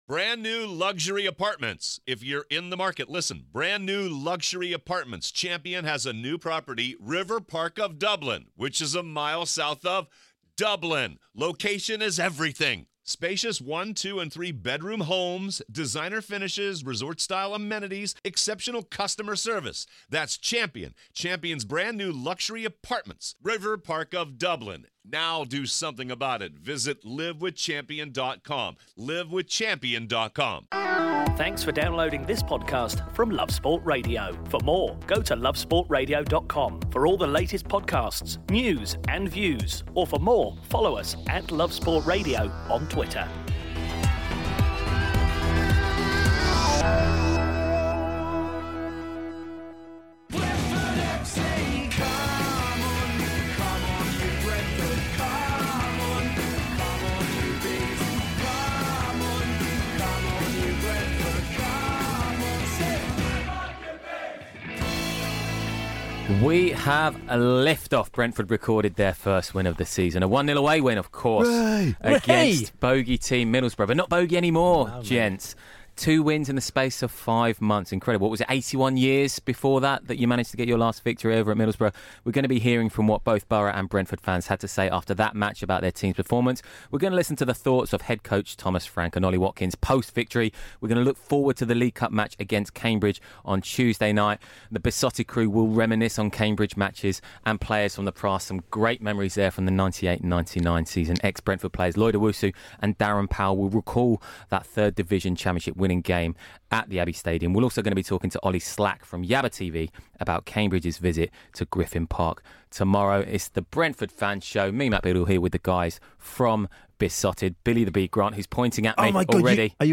chat